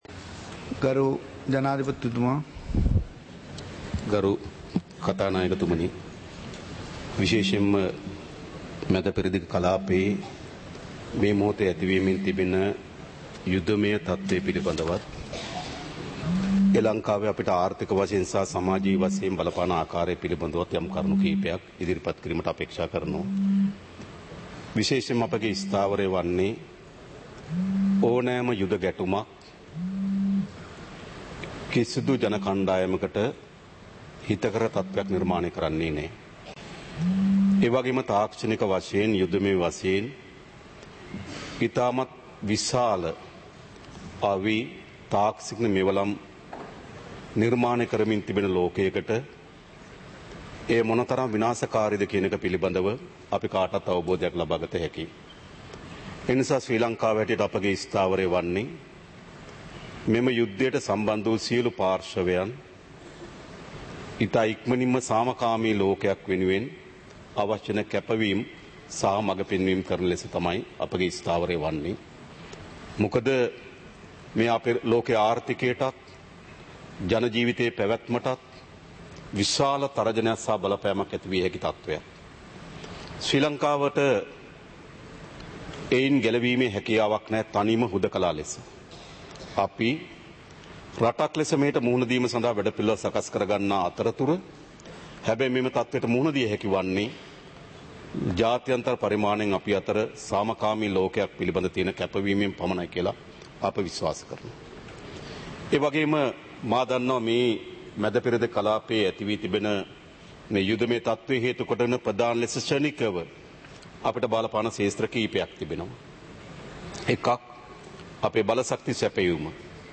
சபை நடவடிக்கைமுறை (2026-03-03)
நேரலை - பதிவுருத்தப்பட்ட